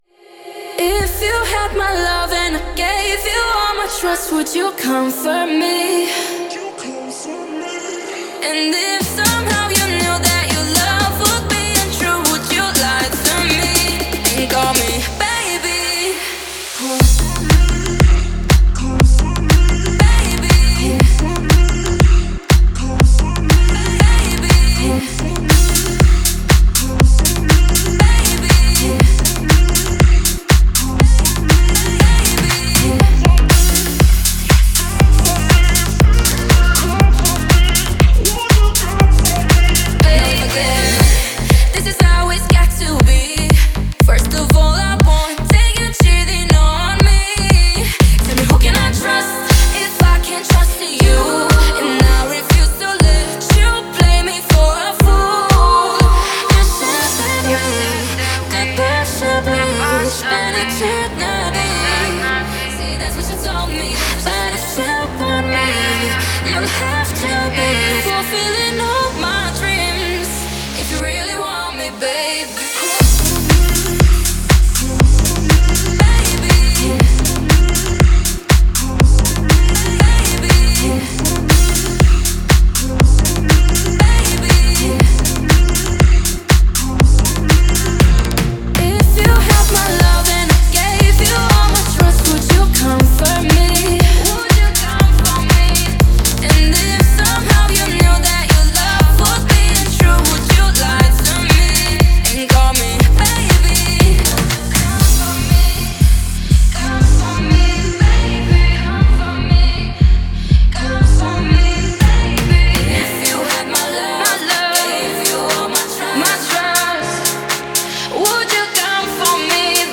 это трек в жанре R&B